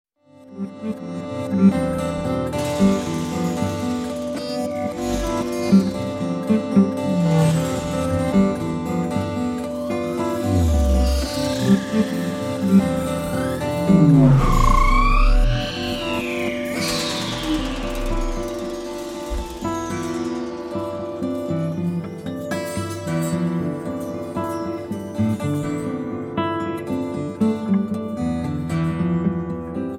Acoustic, Electric Guitar and SuperCollider
Acoustic, Electric Guitar and Percussion